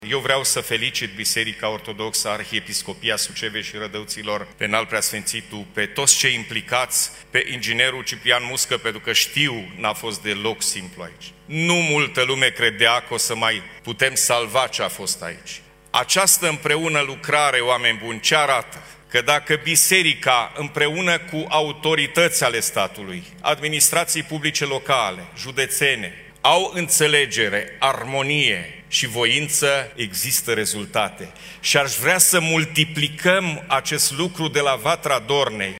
Unul dintre cei care au primit distincții – președintele Consiliului Județean Suceava, GHEORGHE FLUTUR – a salutat readucerea fostului cazinou din Vatra Dornei la strălucirea de altădată.